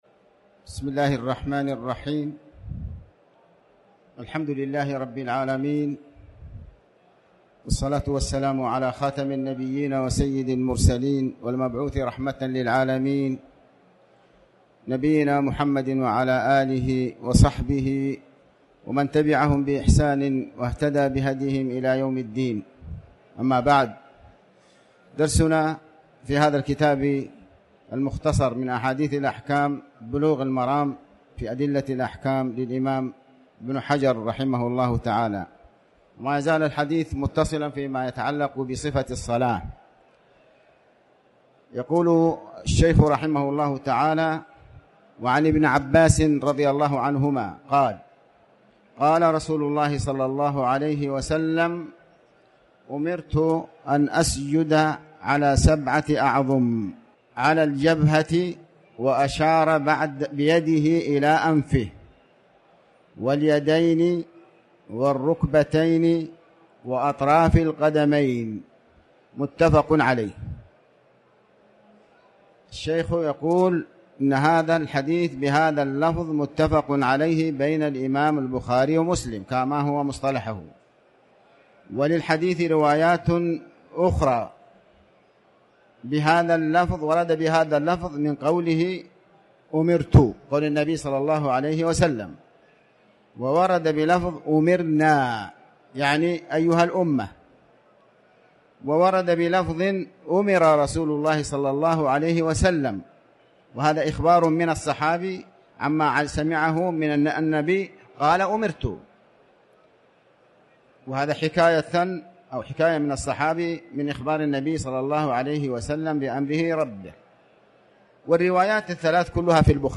تاريخ النشر ٩ صفر ١٤٤٠ هـ المكان: المسجد الحرام الشيخ